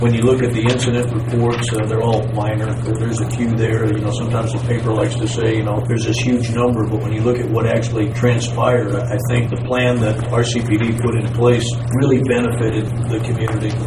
Riley County Law Board member and Manhattan City Commissioner Wynn Butler commented on the public’s perception of Fake Patty’s Day law enforcement tactics during a meeting Tuesday.